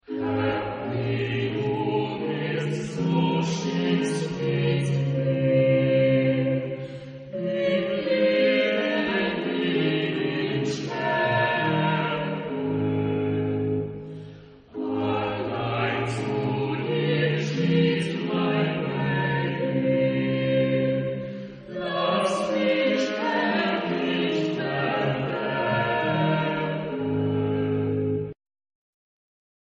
Genre-Style-Form: Cantata ; Sacred
Type of Choir: SATB  (4 mixed voices )
Soloist(s): Sopran (1) / Alt (1) / Tenor (1) / Bass (1)  (3 soloist(s))
Instrumentation: Chamber orchestra  (8 instrumental part(s))
Instruments: Oboe (2) ; Horn (1) ; Violin I ; Violin II ; Violas ; Cello (1) ; Organ (1)
Tonality: G minor